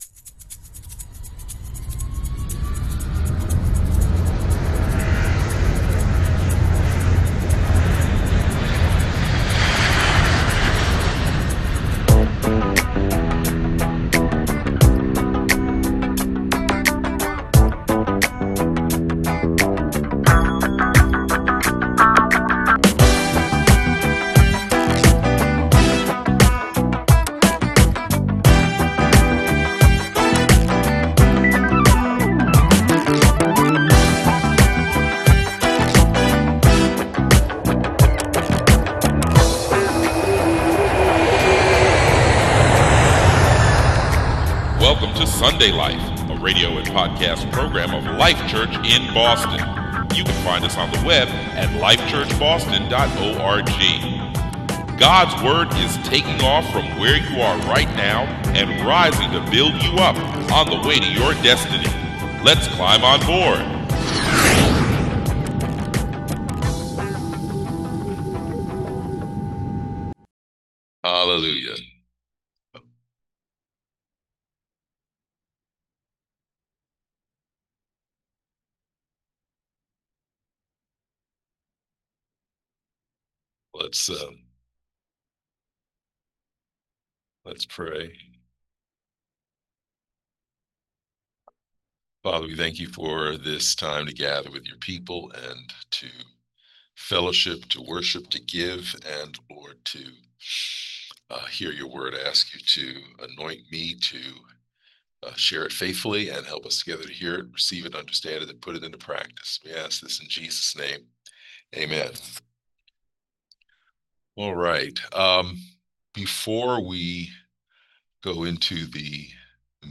Notes for 07/13/2025 Sermon - Life Church